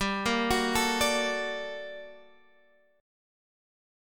GmM9 Chord
Listen to GmM9 strummed